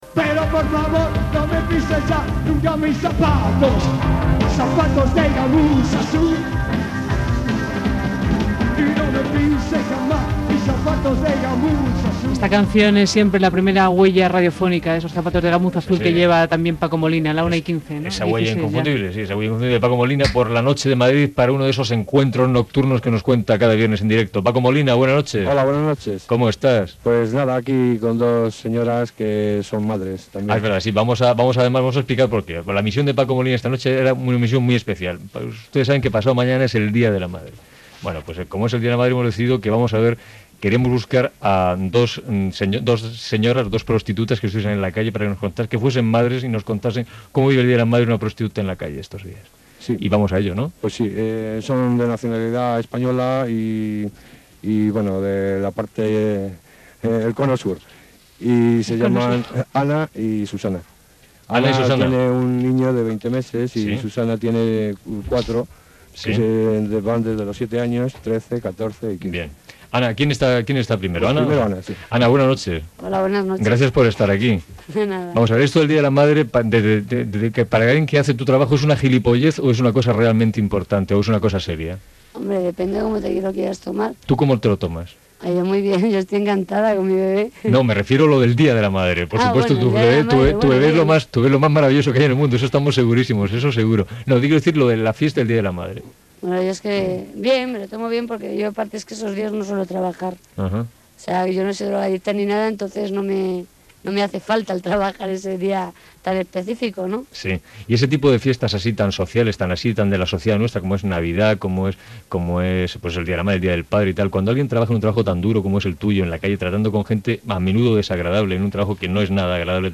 Dues prostitutes de Madrid, que són mares, opinen sobre la Diada de la Mare (de l'1 de maig) i de la seva feina
Info-entreteniment